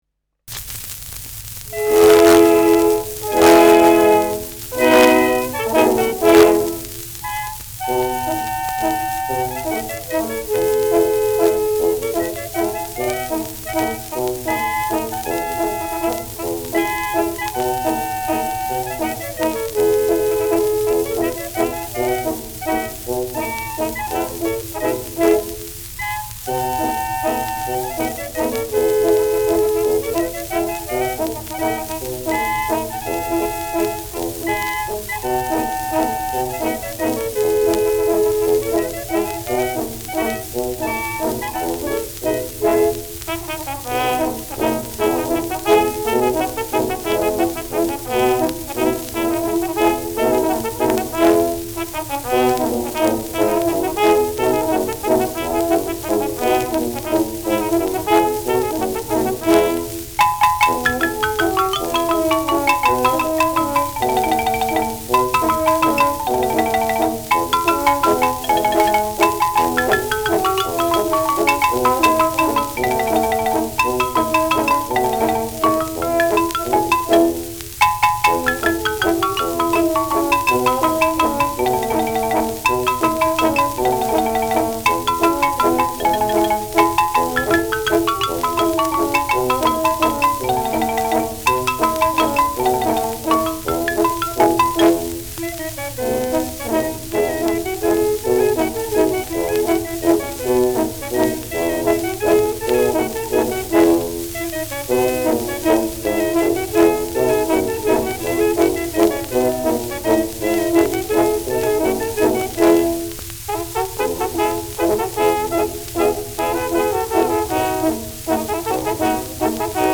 Schellackplatte
präsentes Rauschen : leichtes Knistern
Laudacher Bauernkapelle (Interpretation)
Das hier zu hörende Xylophon-Instrument ist auch unter der Bezeichnung „Hözernes Gelächter“ oder „Hözernes G’lachter“ bekannt.